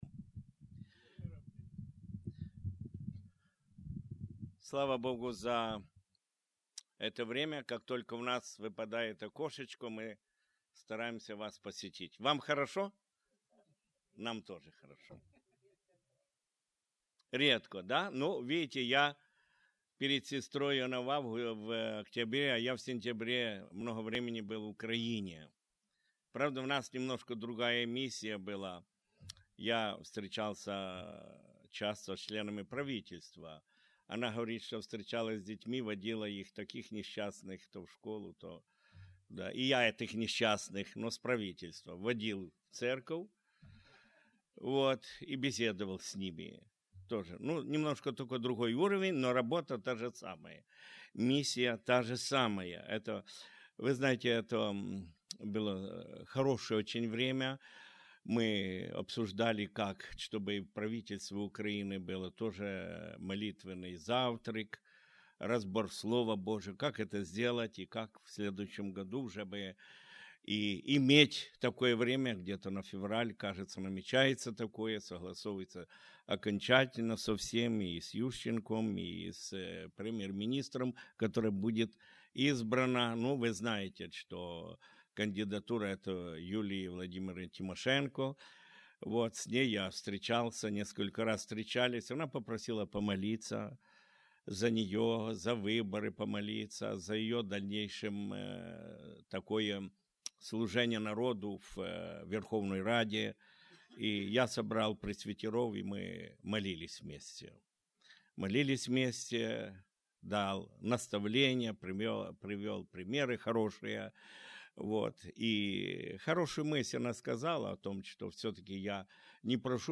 (Филиппийцам 2:5-11) Related Tagged with Воскресные Богослужения